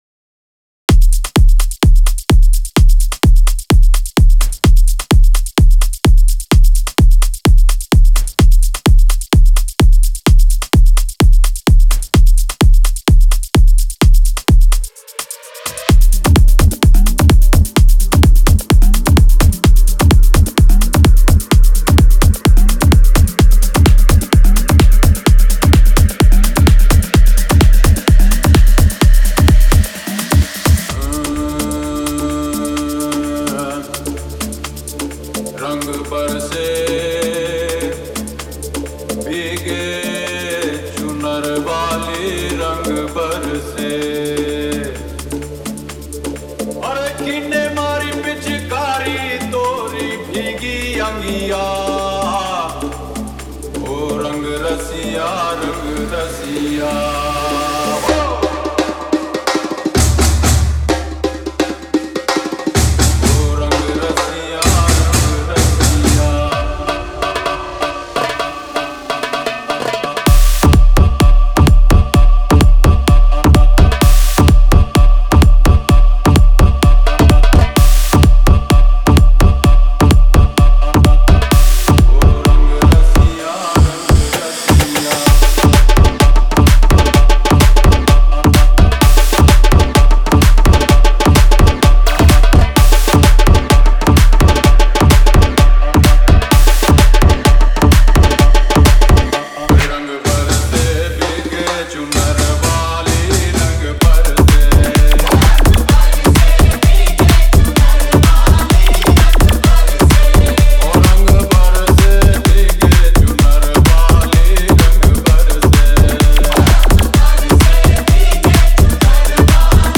Category: DJ SINGLES